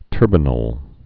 (tûrbə-nəl)